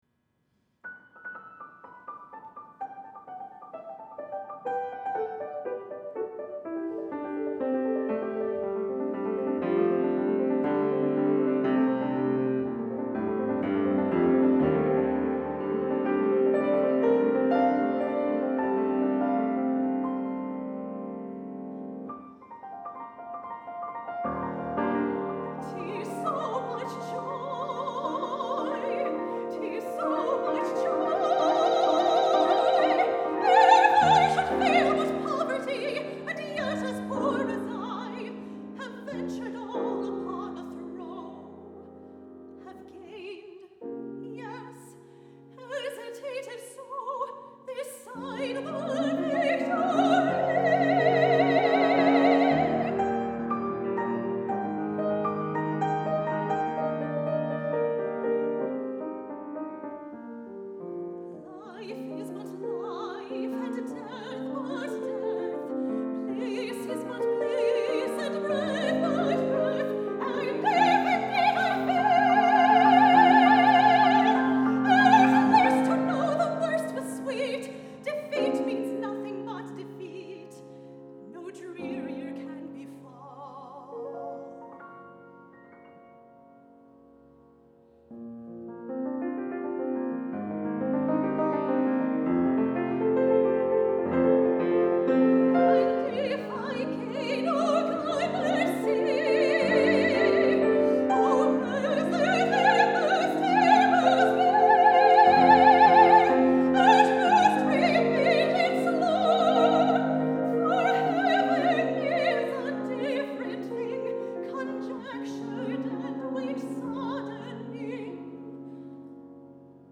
for Soprano and Piano (2014)